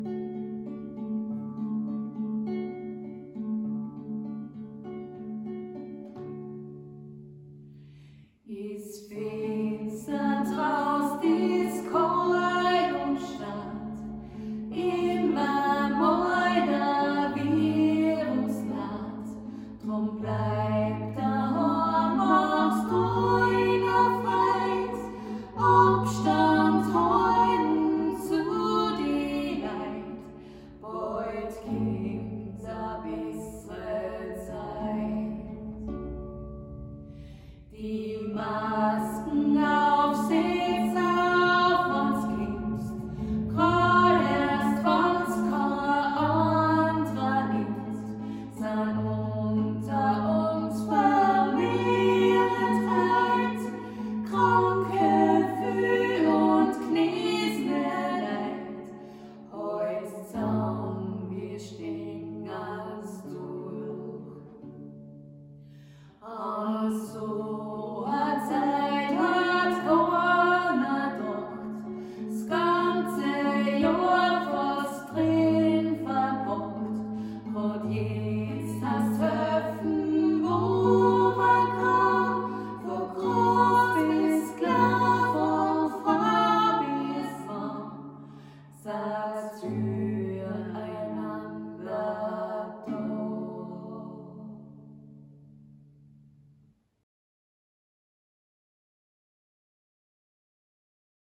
Is finsta draußt - Adventlied Coronaversion